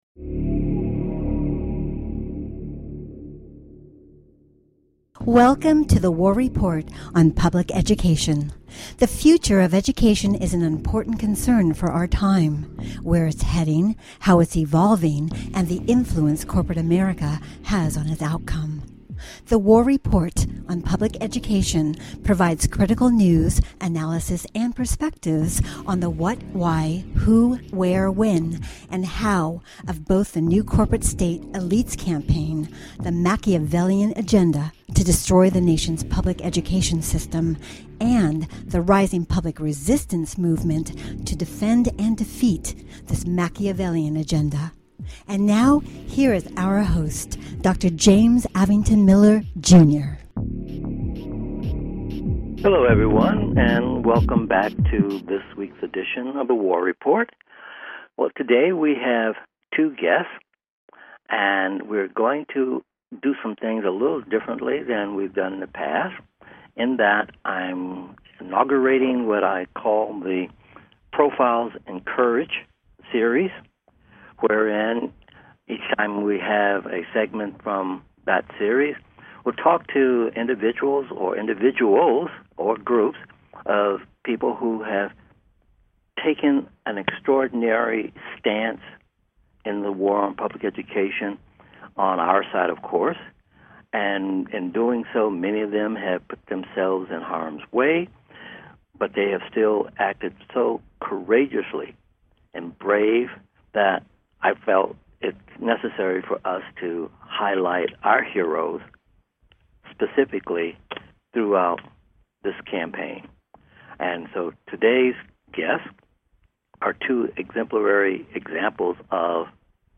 Headlined Show, The War Report on Public Education July 5, 2015